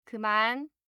알림음(효과음) + 벨소리
알림음 8_그만1-여자.mp3